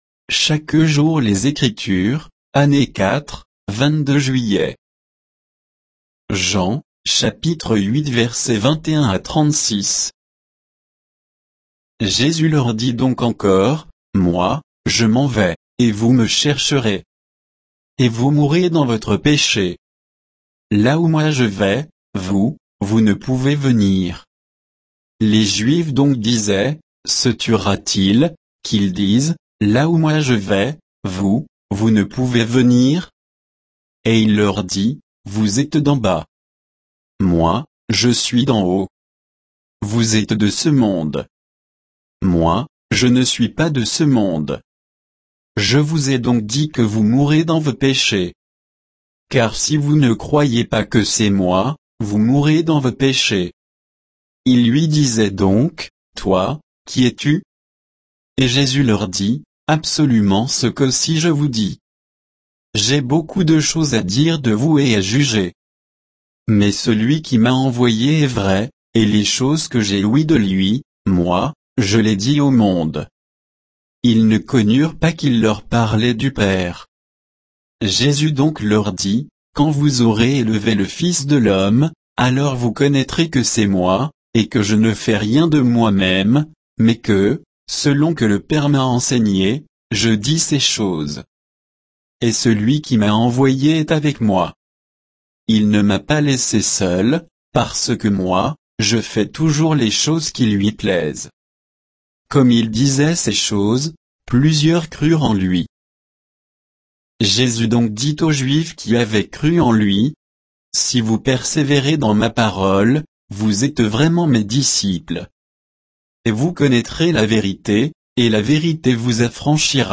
Méditation quoditienne de Chaque jour les Écritures sur Jean 8, 21 à 36